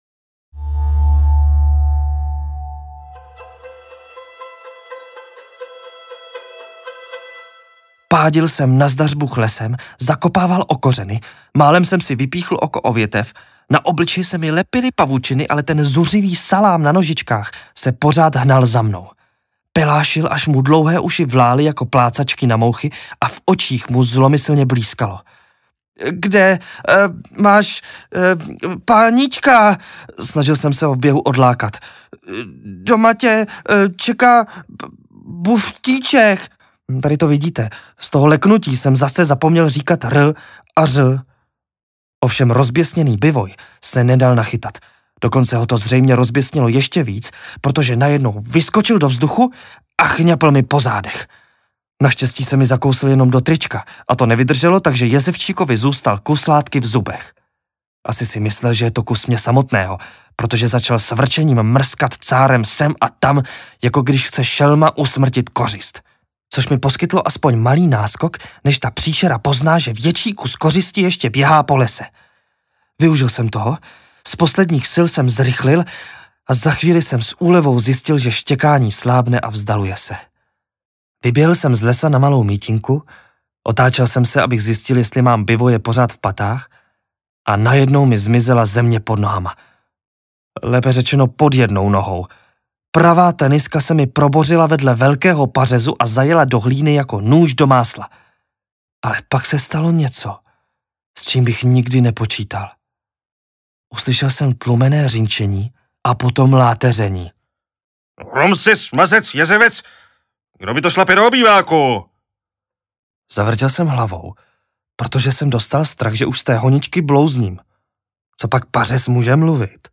Černobílé prázdniny audiokniha
Ukázka z knihy